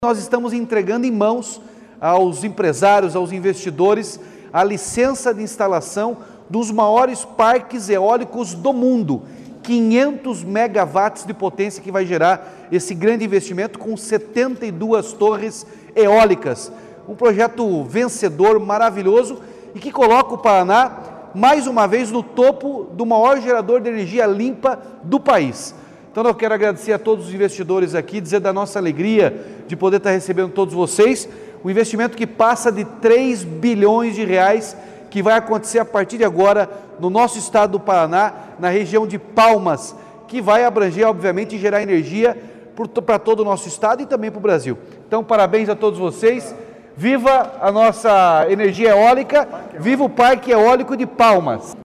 Sonora do governador Ratinho Junior sobre a licença ambiental para o Complexo Eólico Palmas II